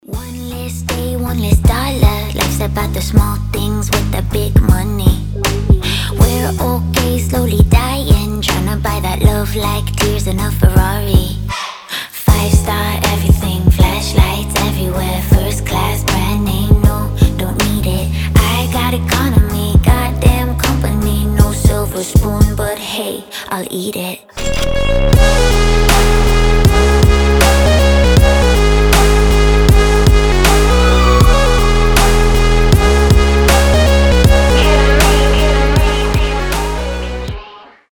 • Качество: 320, Stereo
ритмичные
красивый женский голос
alternative
озорные
Electropop